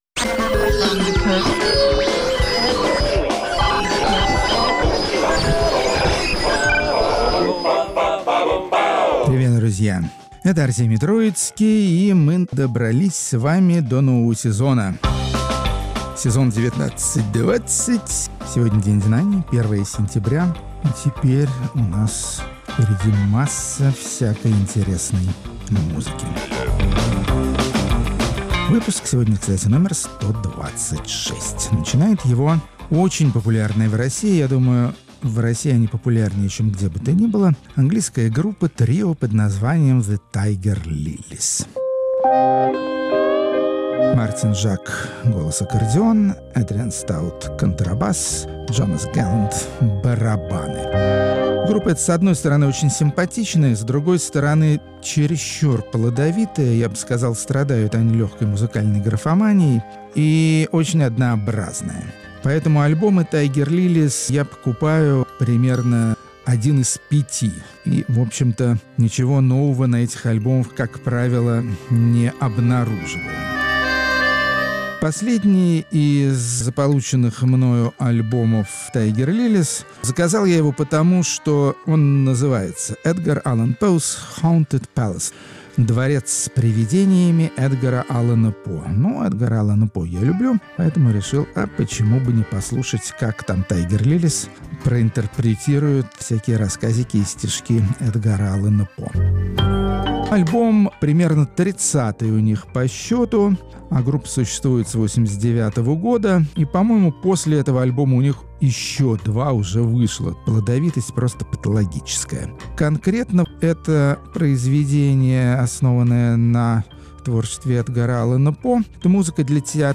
Музыка на Свободе. 1 сентября, 2019 Сто двадцать шестого выпуска программы "Музыка на Свободе", открывающей сезон 2019–2020, – норвежские исполнители инди- и электронной музыки.